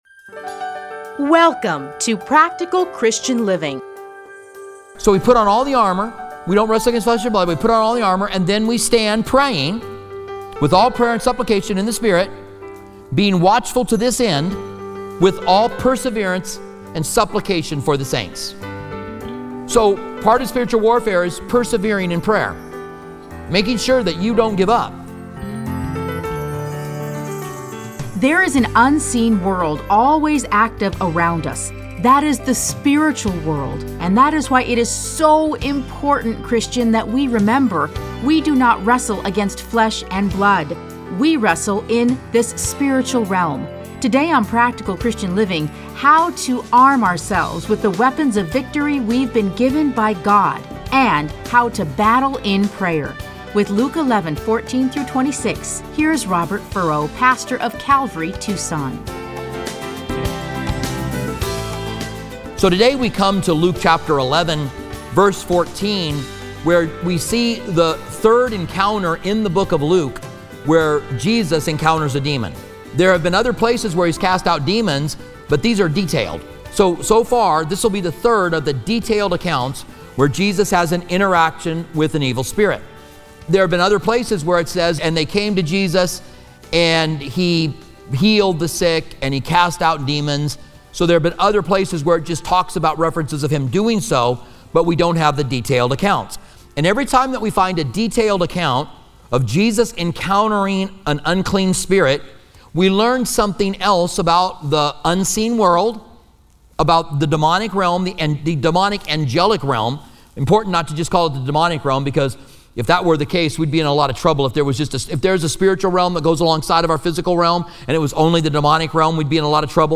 Listen to a teaching from Luke Luke 11:14-26.